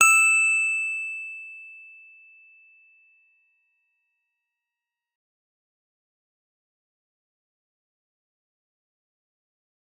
G_Musicbox-E6-f.wav